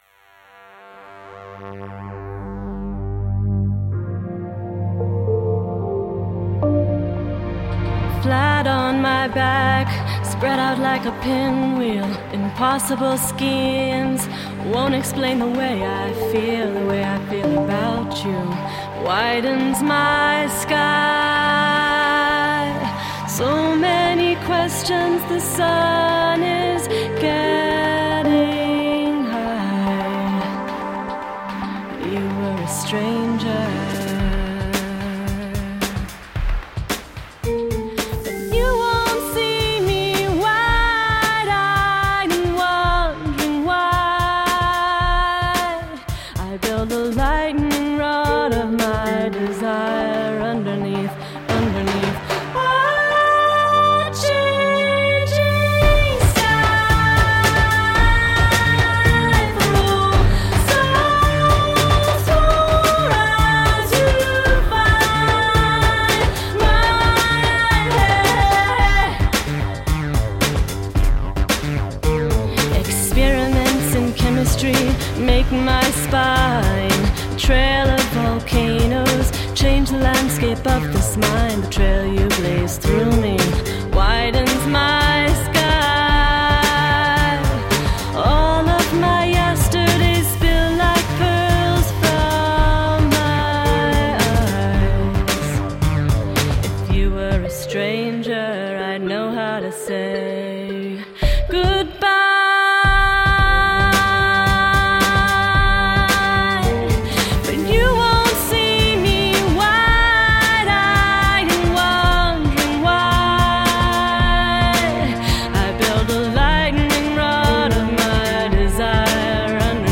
Alternative / downtempo / electro-pop.